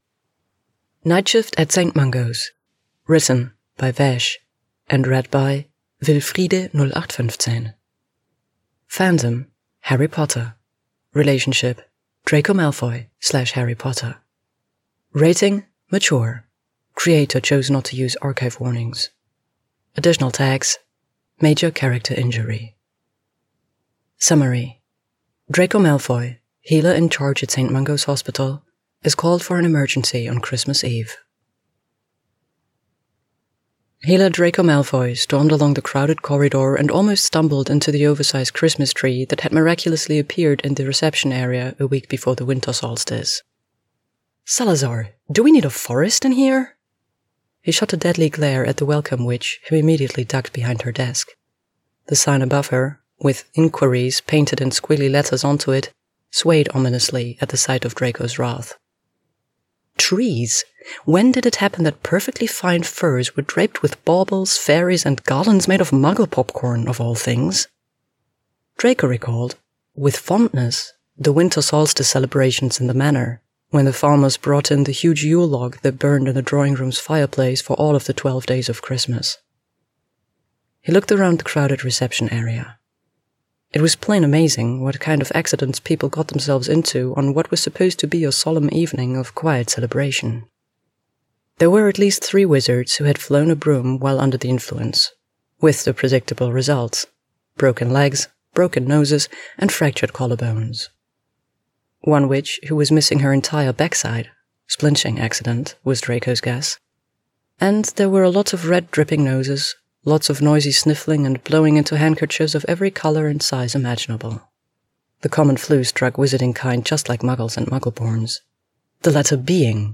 with music & sfx: download mp3: here (r-click or press, and 'save link') [31 MB, 00:21:05]